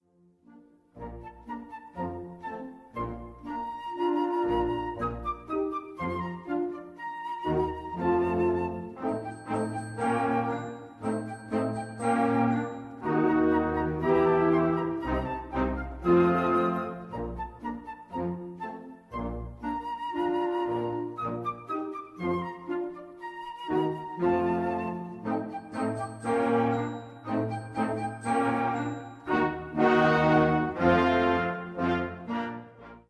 Gattung: Weihnachtliche Musik - Jugend
Besetzung: Blasorchester